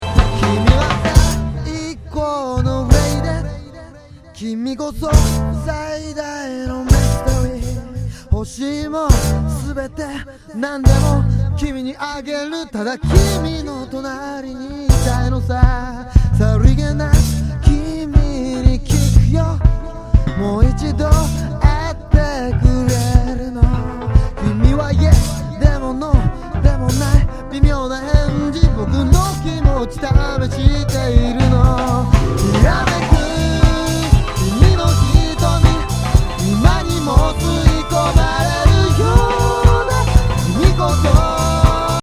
前作とはかなり様相を変え、ハウスやラウンジを強く意識した内容になっている。
全曲にポップな歌がフューチャーされている。